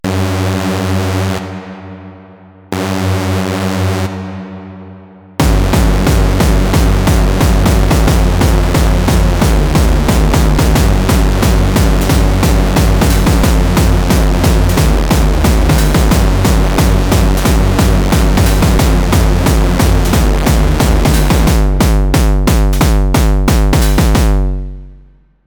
A brief cover of the classic hardcore track